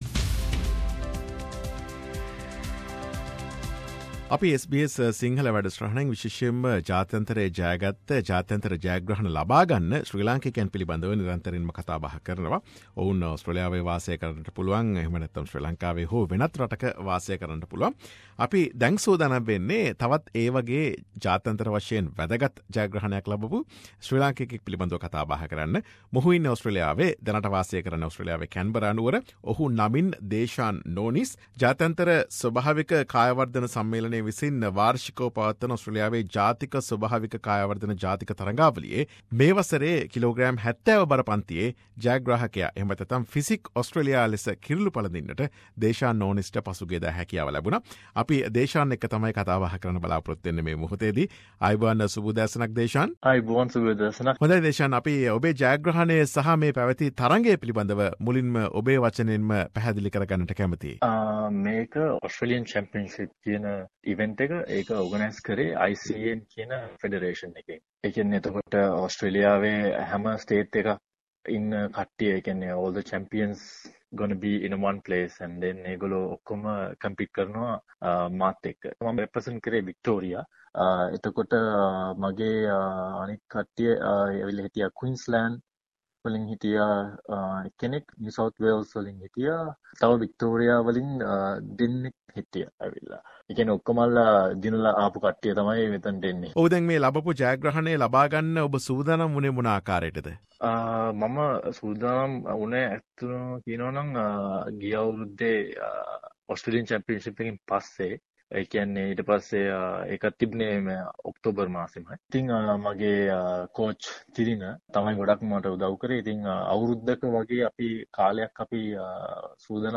SBS සිංහල සමග සිදු කළ සාකච්ඡාව.